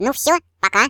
share/hedgewars/Data/Sounds/voices/Default_ru/Byebye.ogg